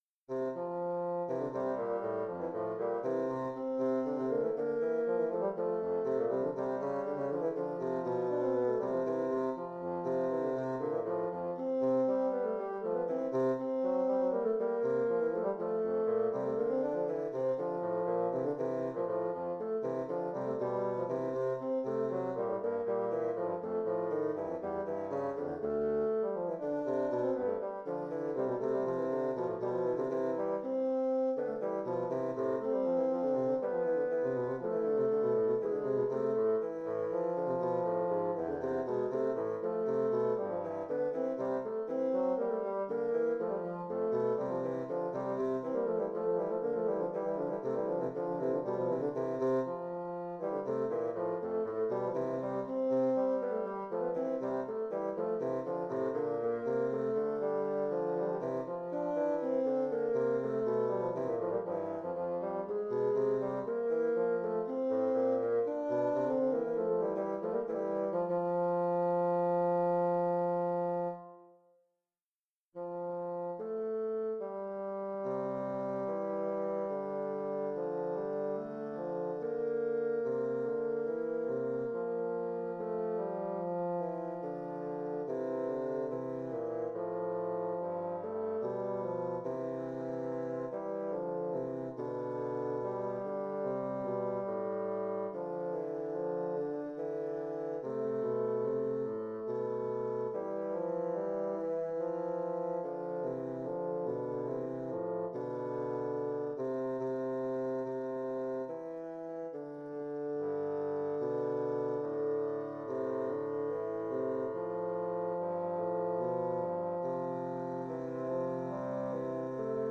Intermediate bassoon duet
Instrumentation: Bassoon duet
tags: bassoon music